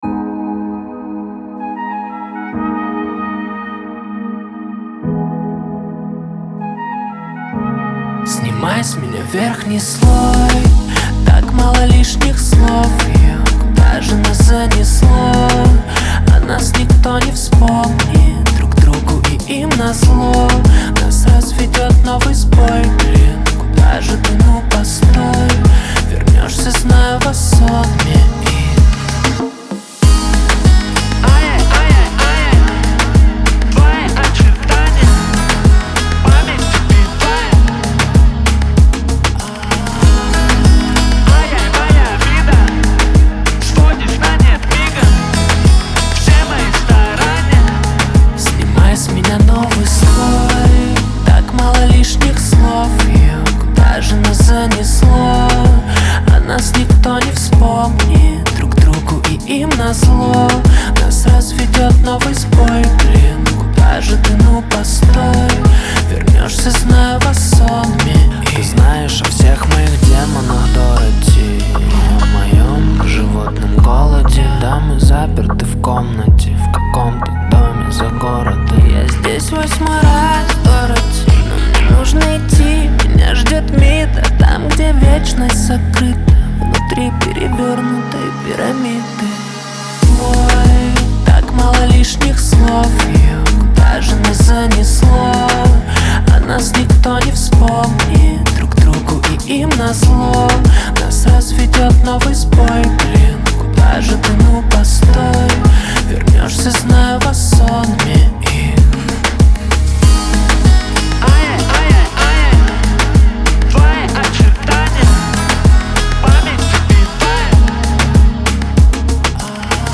это мощная песня в жанре хип-хоп
насыщенные биты и глубокий, эмоциональный голос